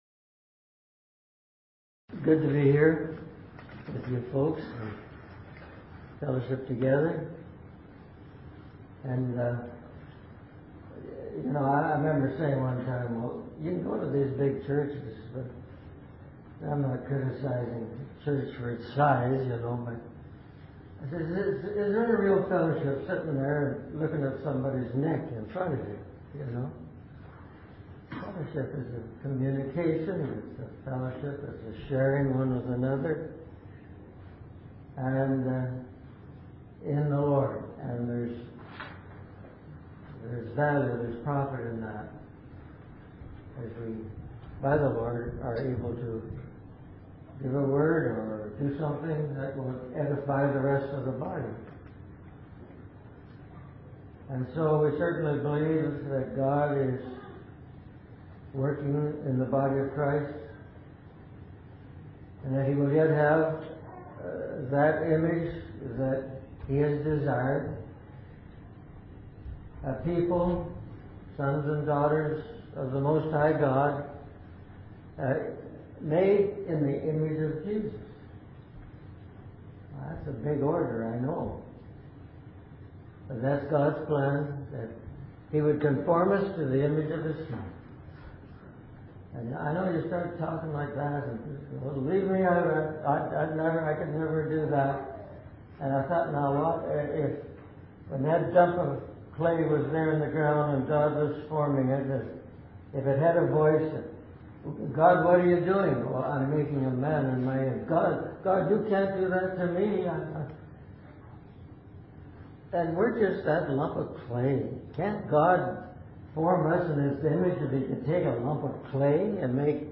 In this sermon, the speaker shares a story about a family gathering where the children learned about God's ways. He emphasizes the importance of being faithful in both small and big things, as it determines whether God will entrust us with true riches.